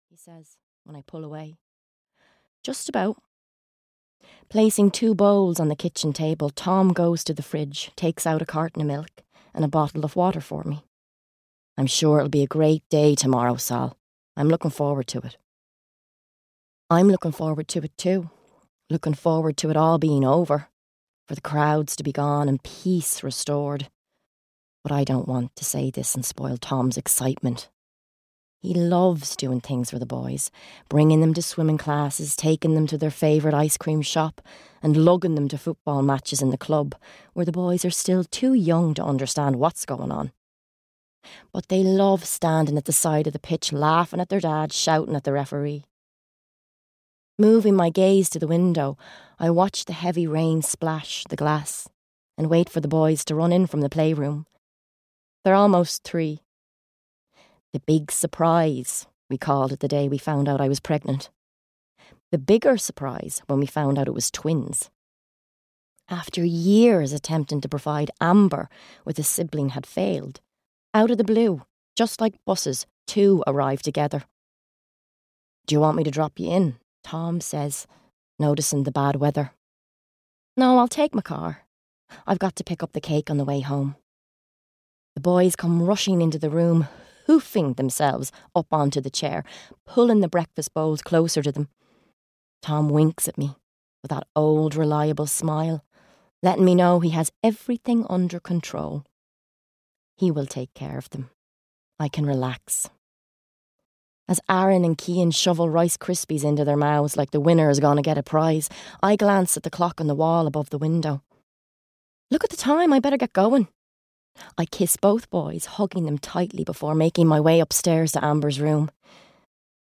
The Secrets He Kept (EN) audiokniha
Ukázka z knihy